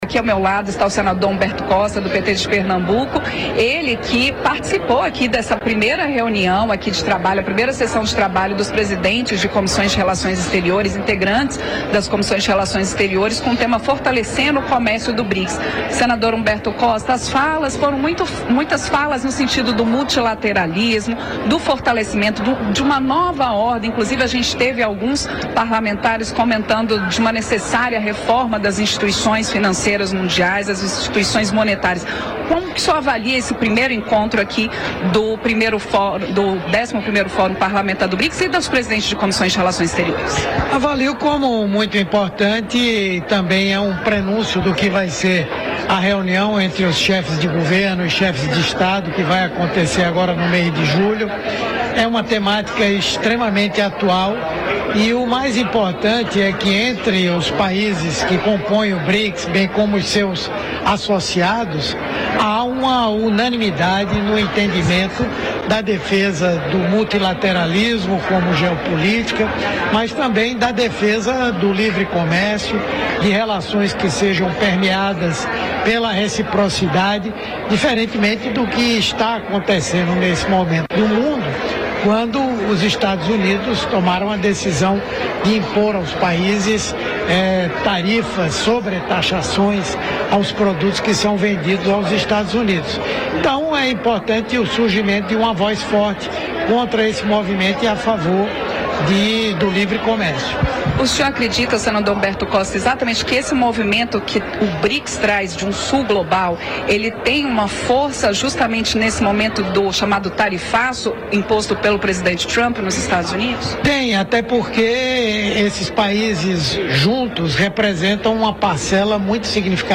O multilateralismo e o livre comércio foram alguns dos temas tratados nesse primeiro encontro, além do fortalecimento do Brics como alternativa à hegemonia norte-americana no cenário internacional. Ouça a entrevista de Humberto Costa